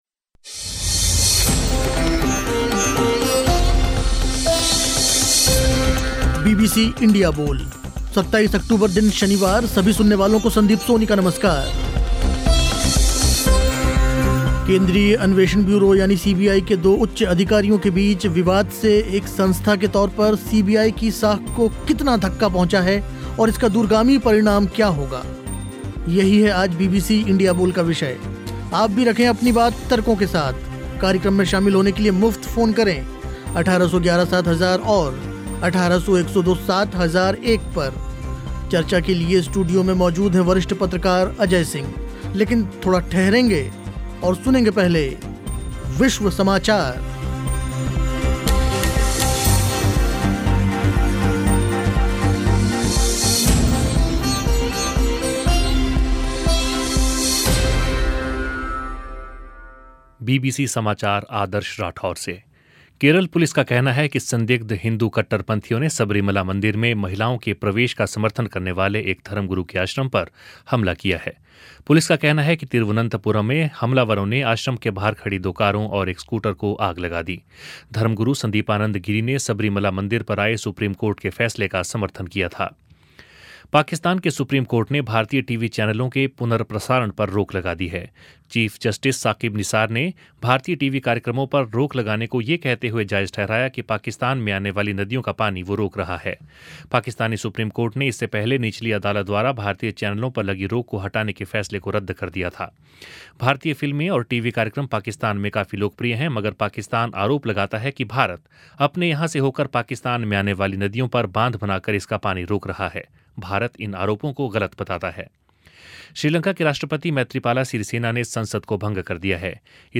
बीबीसी इंडिया बोल में चर्चा हुई इसी विषय पर